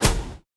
WHOOSH_Steam_Fast_04_mono.wav